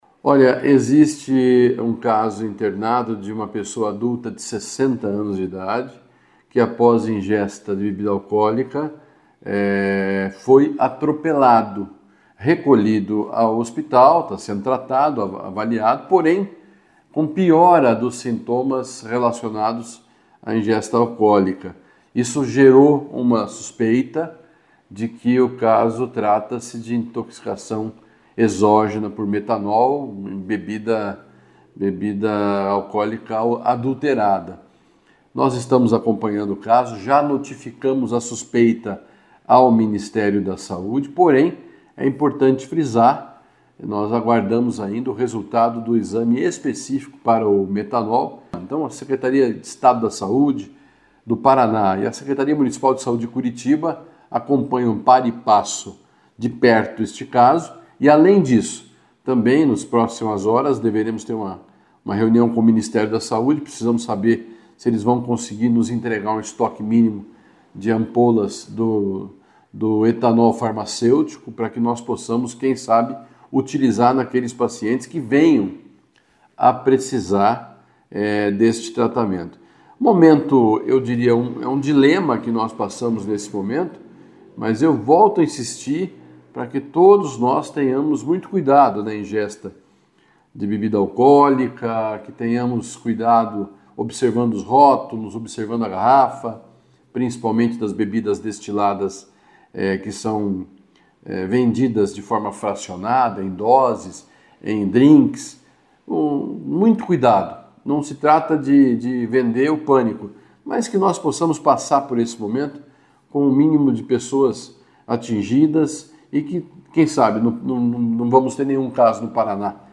Ouça o que diz o secretário de Saúde, Beto Preto, sobre o caso: